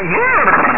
Brrrr! Sound Effect
brrrr.mp3